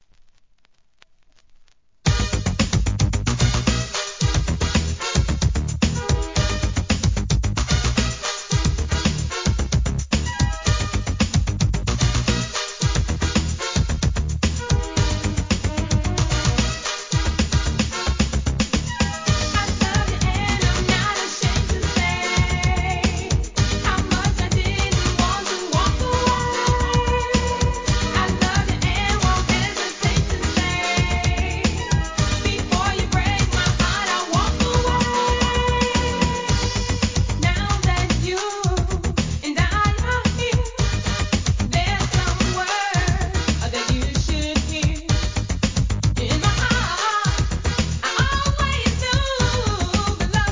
SOUL/FUNK/etc...
エレクトロ・ダンス・ナンバー